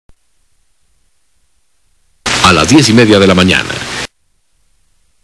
（アラス　１０　デラ　イ　メディア　マニャーナ）